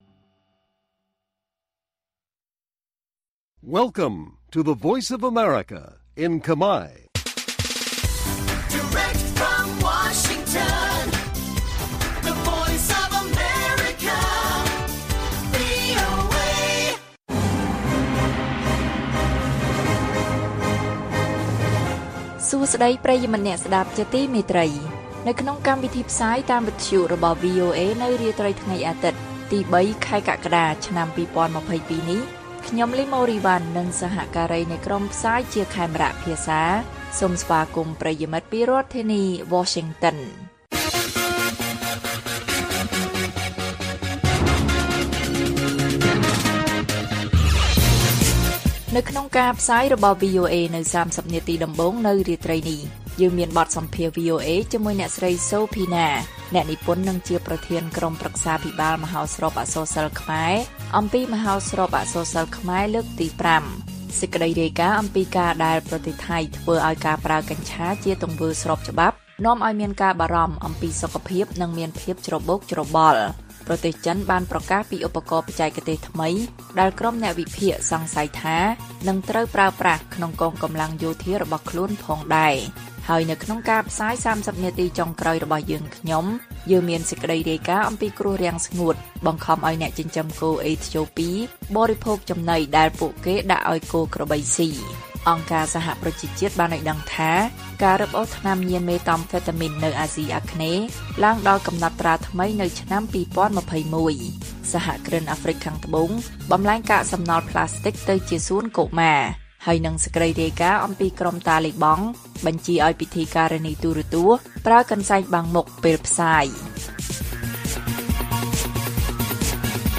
ព័ត៌មានពេលរាត្រី ៣ កក្កដា៖ បទសម្ភាសន៍ VOA អំពីមហោស្រពអក្សរសិល្ប៍ខ្មែរលើកទី៥